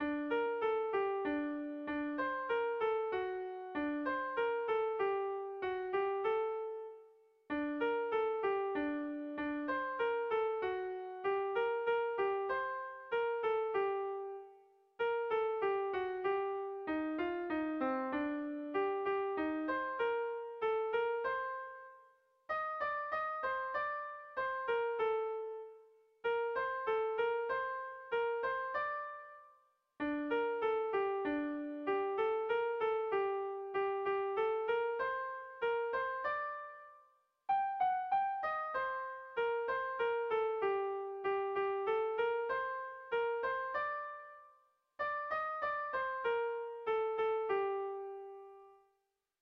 A1A2BDE..